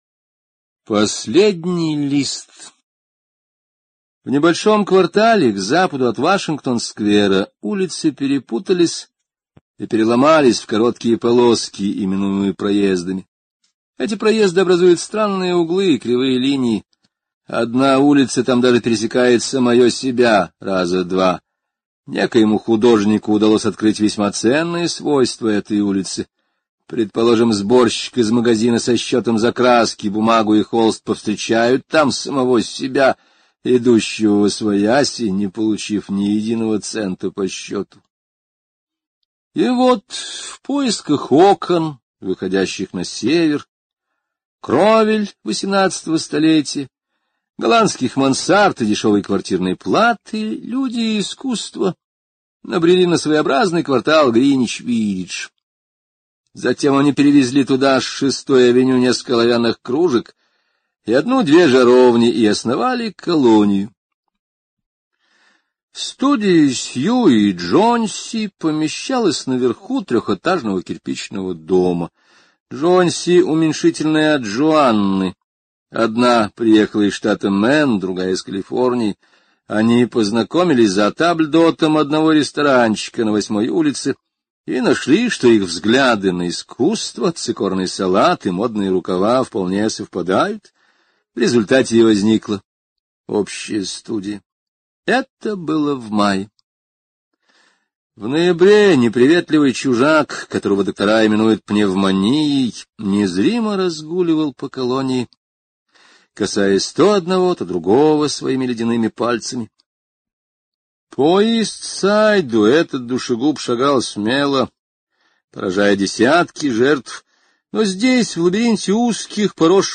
Последний лист — слушать аудиосказку Генри О бесплатно онлайн